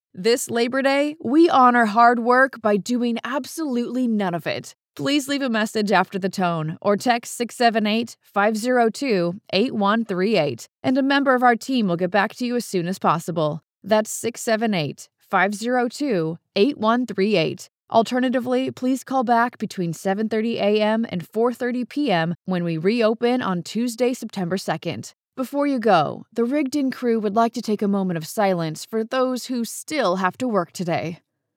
Natural, Urbana, Cálida
Telefonía